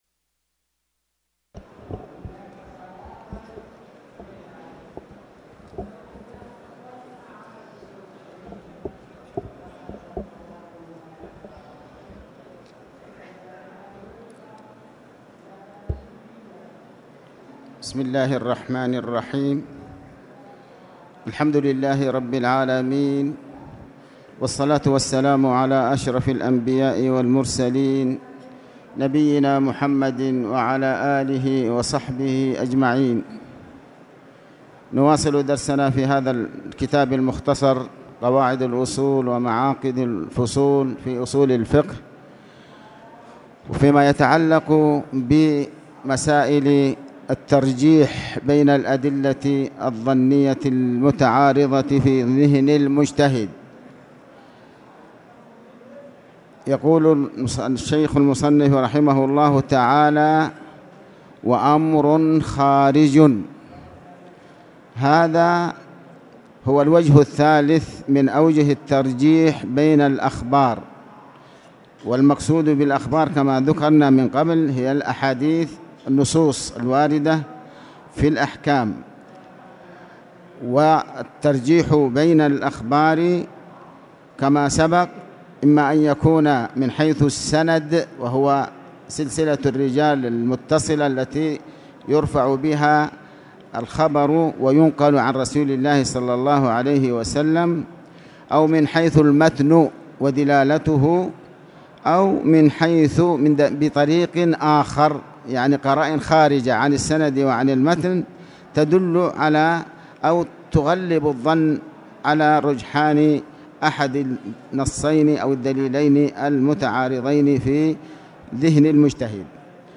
تاريخ النشر ٢٢ رجب ١٤٣٨ هـ المكان: المسجد الحرام الشيخ